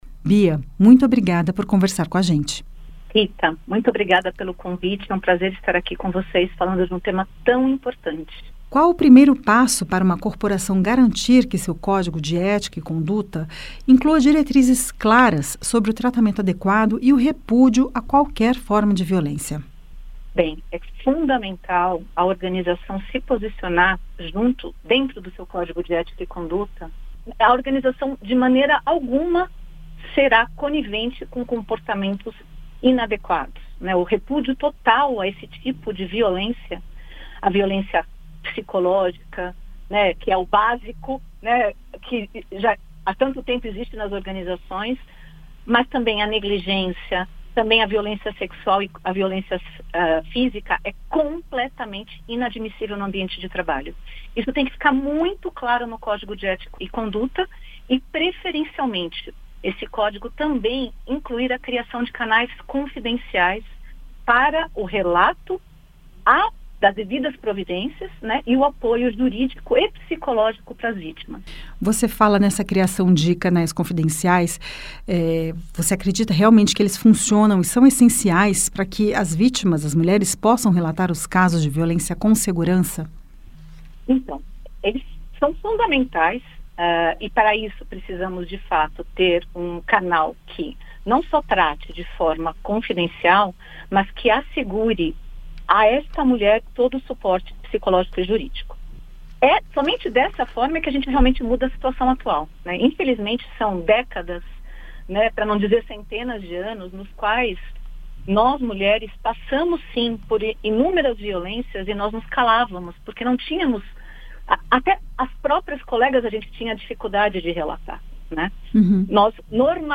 Especialista fala como combater o assédio no ambiente de trabalho